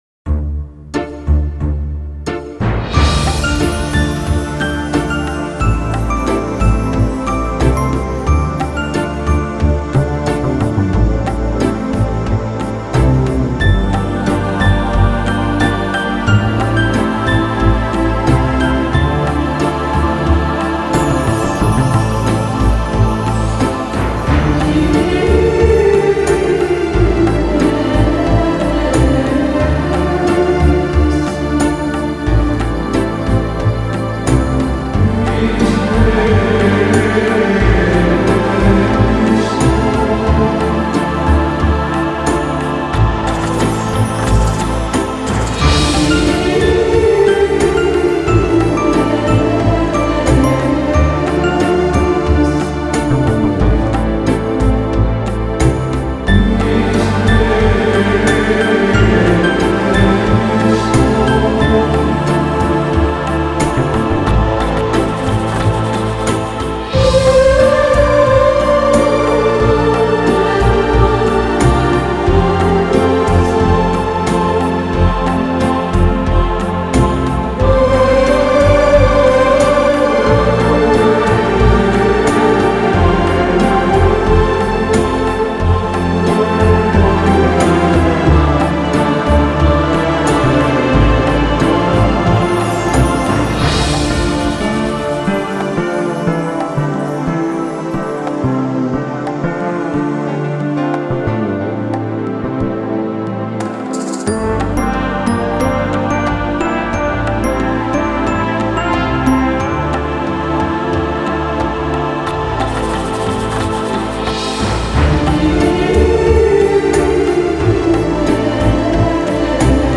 Canto gregoriano